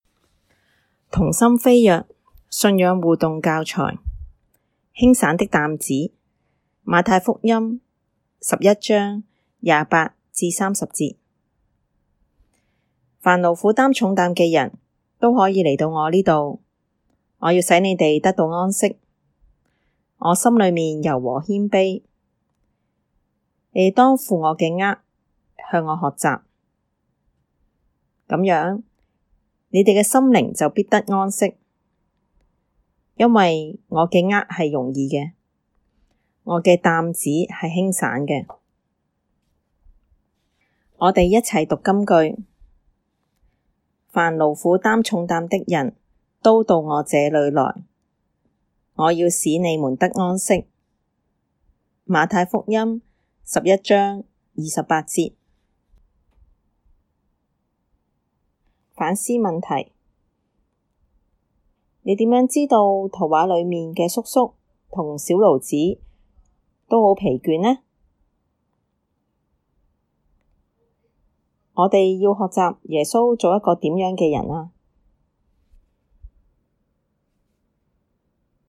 我們鼓勵父母與子女在信仰上互動, 所以製作了語言樣本給大家參考。